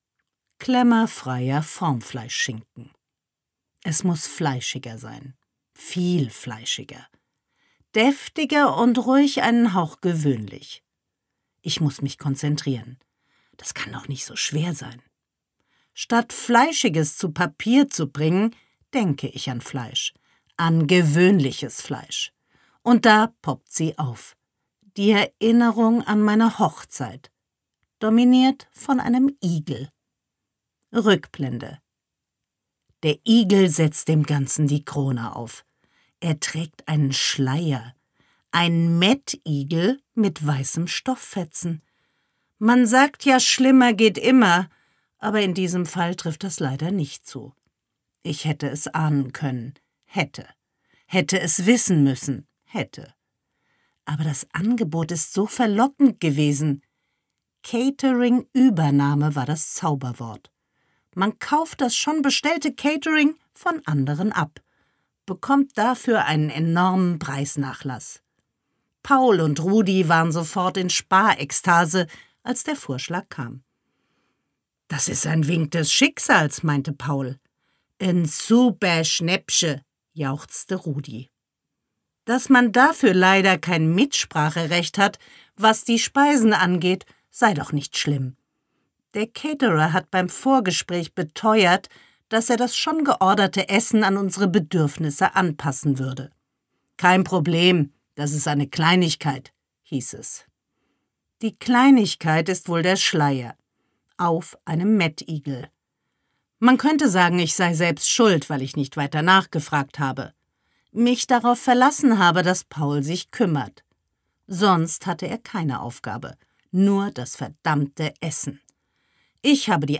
Susanne Fröhlich ist eine begnadete Interpretin ihrer eigenen Romane.
Gekürzt Autorisierte, d.h. von Autor:innen und / oder Verlagen freigegebene, bearbeitete Fassung.
Ungezügelt Gelesen von: Susanne Fröhlich